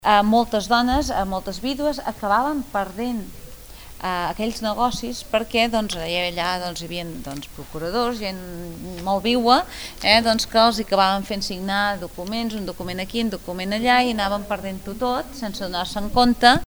Ràdio Capital ha seguit una visita guiada que organitza Begur pels espais més interessant de l’arquitectura i la cultura indiana al municipi.